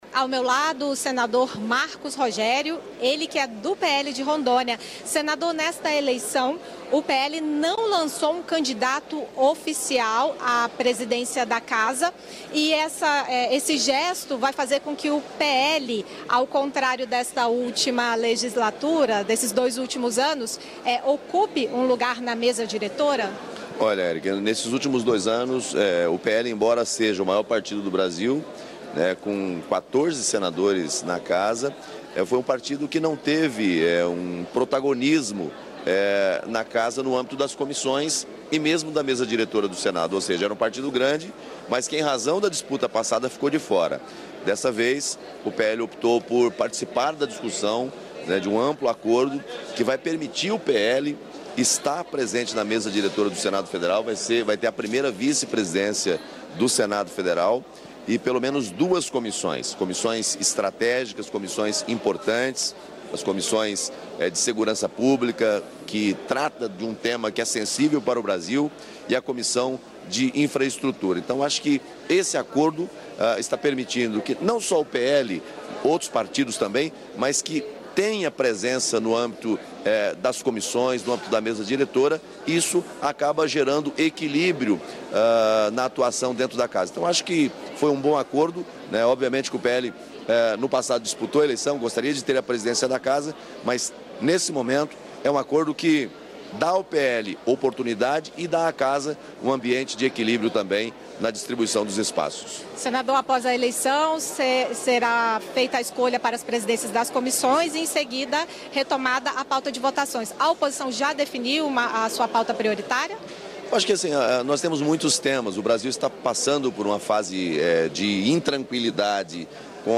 Marcos Rogério também falou sobre as prioridades da Oposição para o ano legislativo de 2025. Confira a íntegra da entrevista.